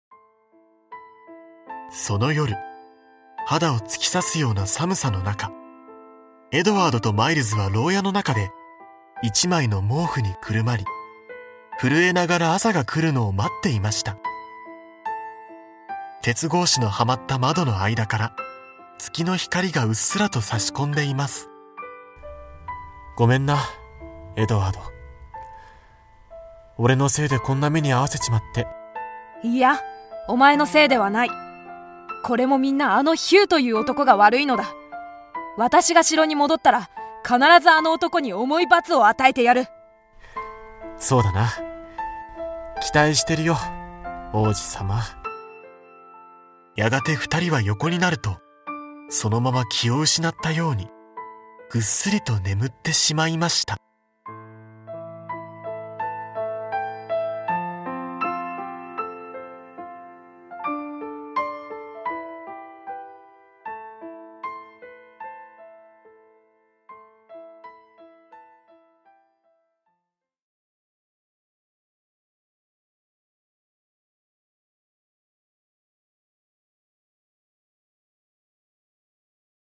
大人も子どもも楽しめる、童話オーディオブック！
大人も子どもも一緒になって、多彩なキャストと、楽しい音楽でお楽しみ下さい。